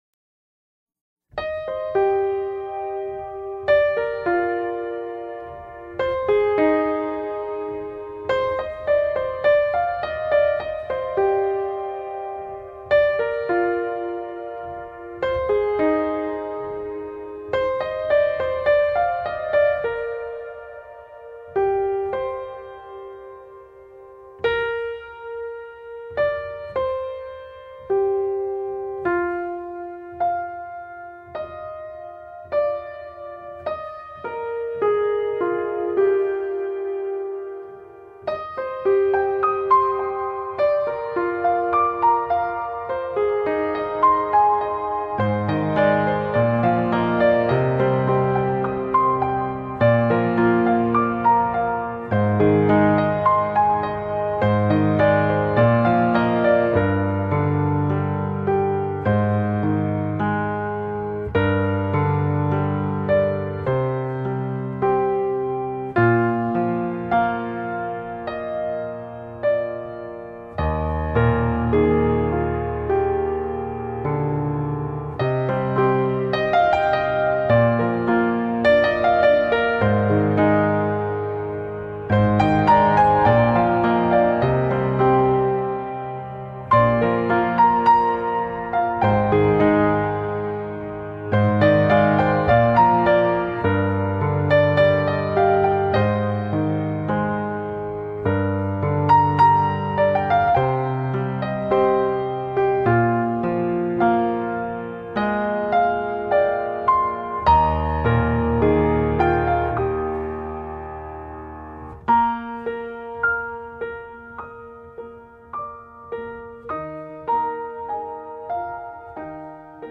电子合成
朴淡雅实的风格，在用心品味后，会发现细腻且亲切的情感……。